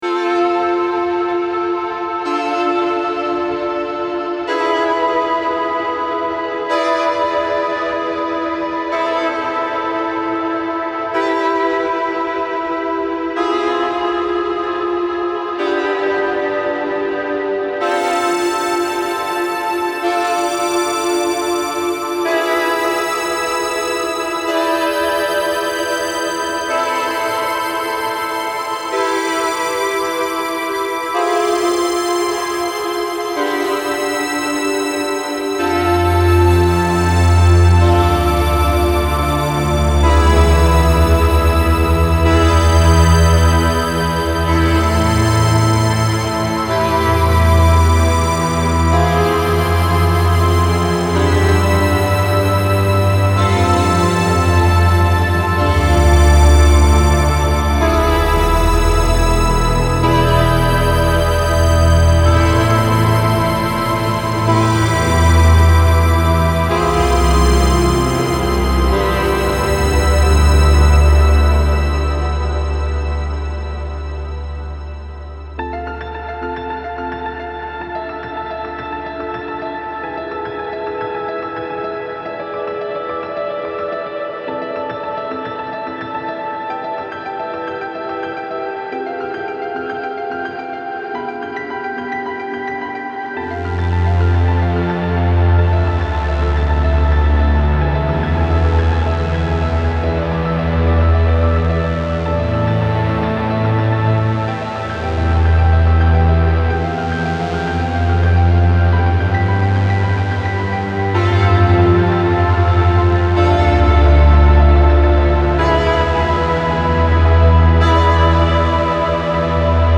Style Style Ambient, Soundtrack
Mood Mood Bright, Intense, Relaxed
Featured Featured Piano, Synth
BPM BPM 108